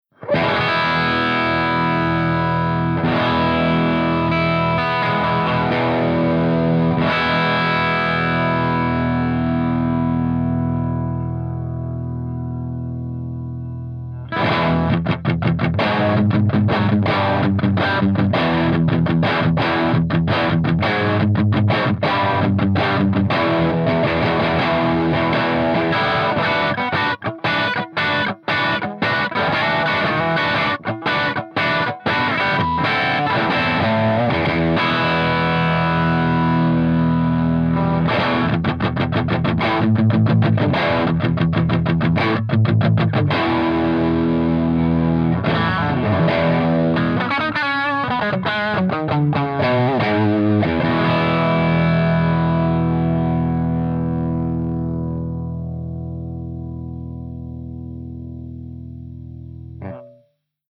130_MARSHALLJCM800_CH2HIGHGAIN_V30_HB